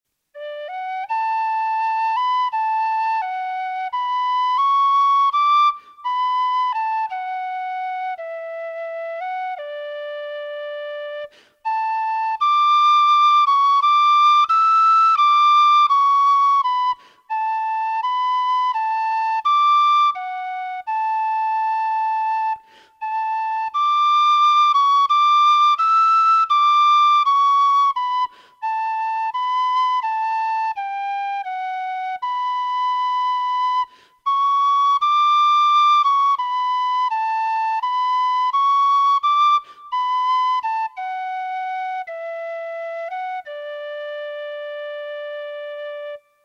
Traditional Irish Music -- Learning Resources Harp that once Thru' Tara's Halls, The (March) /